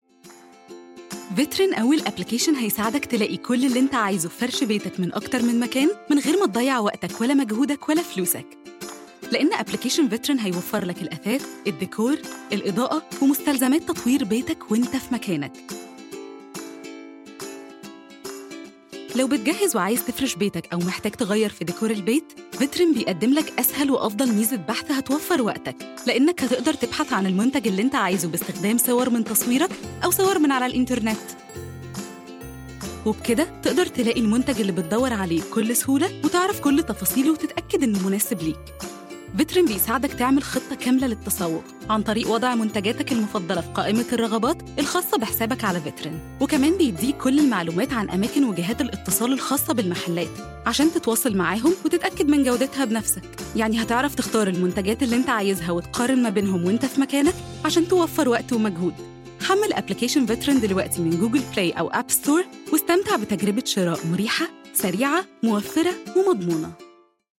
Erklärvideos
Mikrofon: Audio-Technica AT2020 / Neumann U87